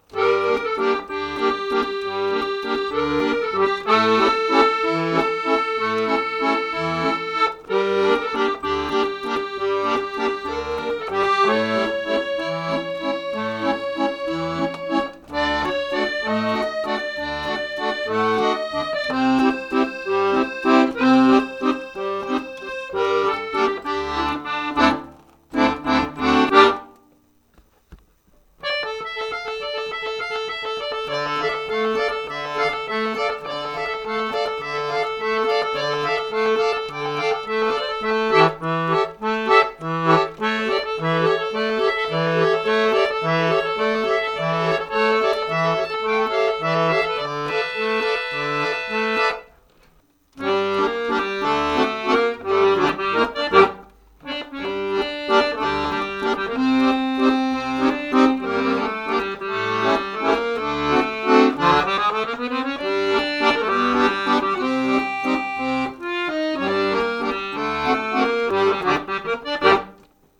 akkordeon.mp3